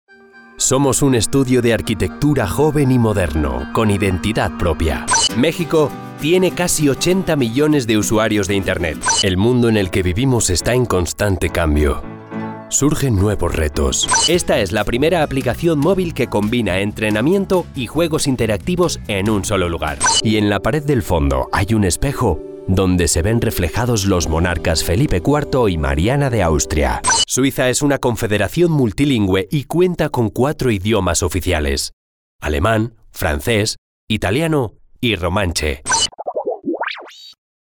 Trustworthy or irreverent personality, fresh & cool.
Sprechprobe: Industrie (Muttersprache):
★MY RIG★ -Mic. RODE NT1A -Preamp. Focusrite Scarlett Solo 2nd gen. -SONY MDR-7506 Headphones -DAW. Adobe Audition CC 2019 -Intel i5 / 16 RAM Gb